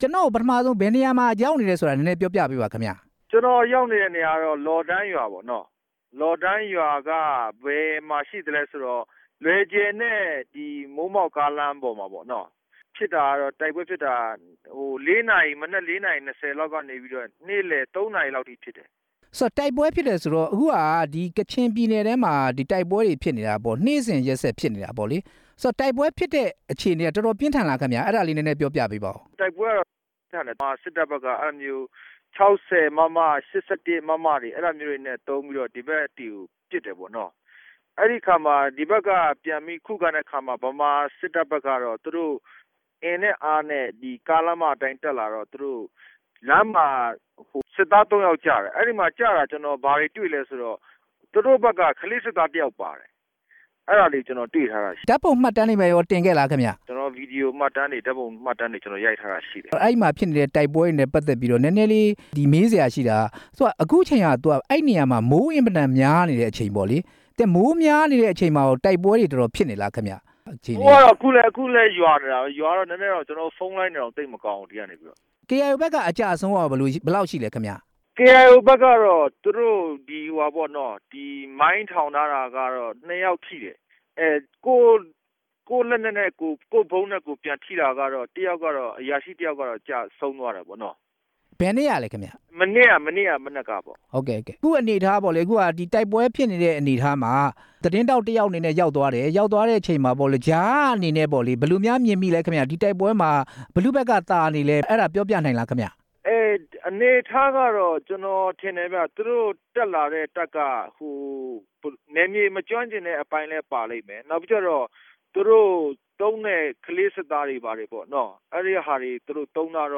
အစိုးရတပ်နဲ့ KIO တိုက်ပွဲ သတင်းထောက်နဲ့ မေးမြန်းချက်